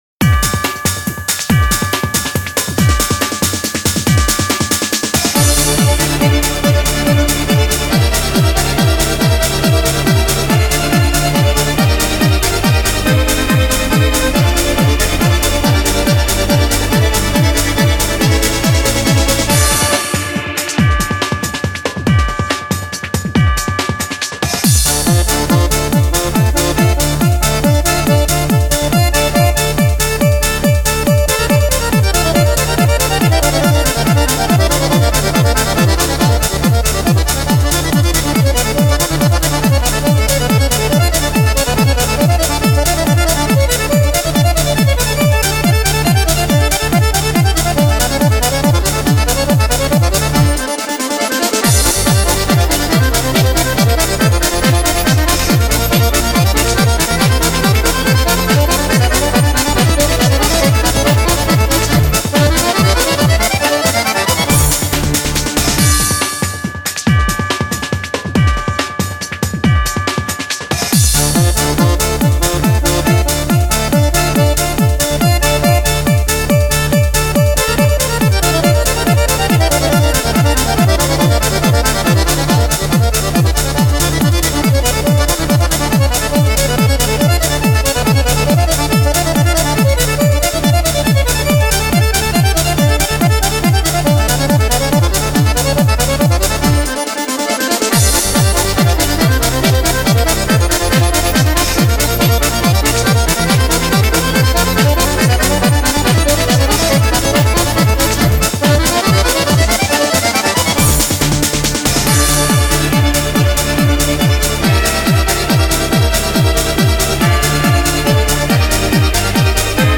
свой неповторимый стиль виртуозной игры на двух баянах